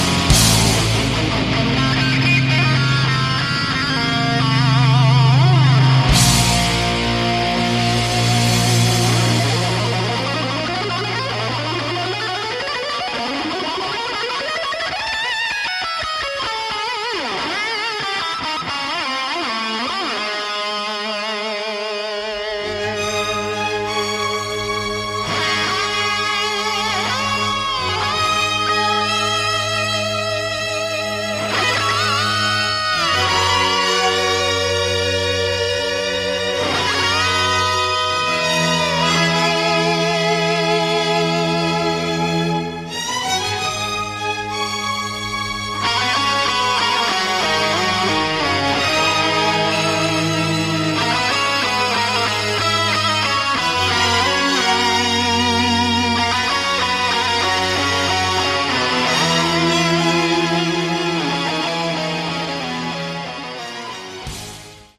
Category: AOR
lead and backing vocals
electric and acoustic guitars
drums, percussion
keyboards